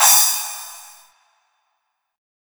• Short Reverb Cymbal Drum Sample C# Key 02.wav
Royality free cymbal drum sample tuned to the C# note. Loudest frequency: 8297Hz
short-reverb-cymbal-drum-sample-c-sharp-key-02-kGg.wav